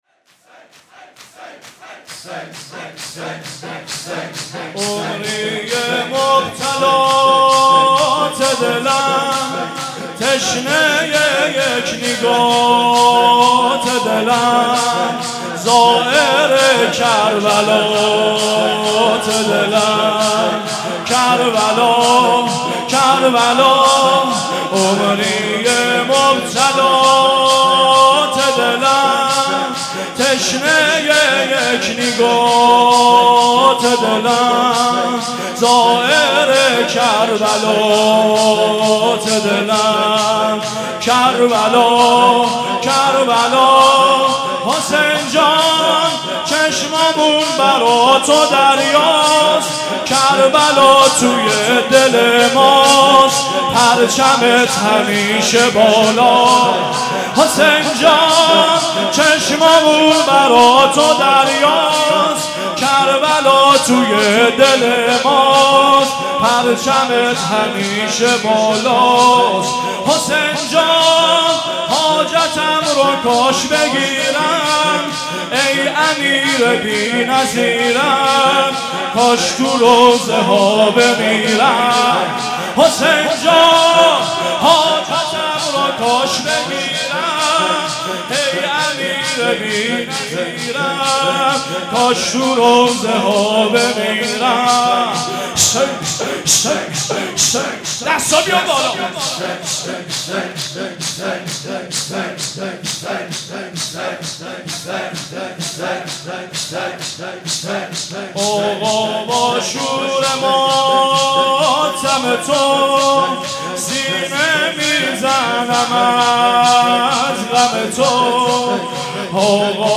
صوت مراسم شب ششم محرم ۱۴۳۷ دانشگاه امیرکبیر و حسینیه حاج همت ذیلاً می‌آید: